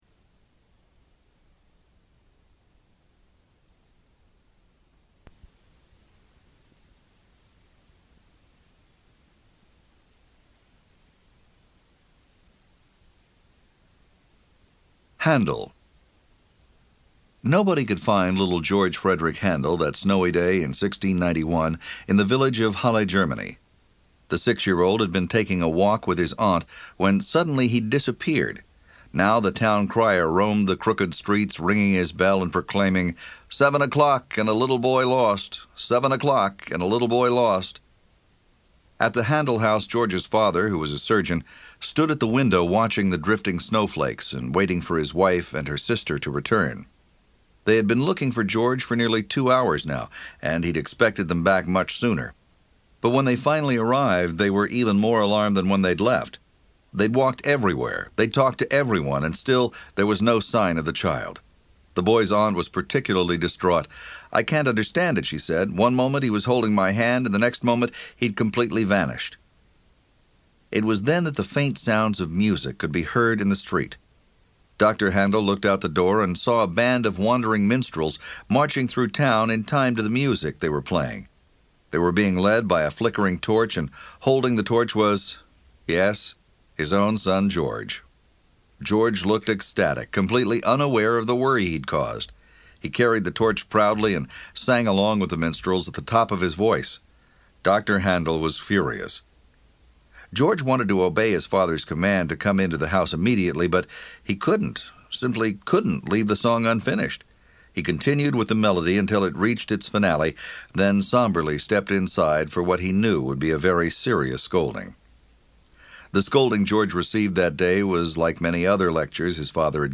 Genre: Books & Spoken.